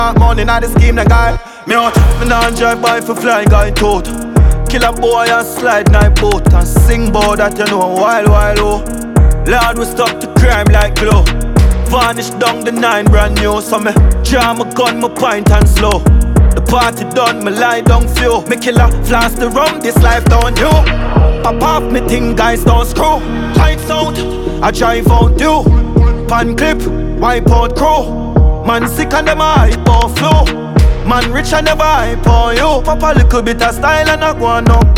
Off-beat гитары и расслабленный ритм
2025-05-23 Жанр: Регги Длительность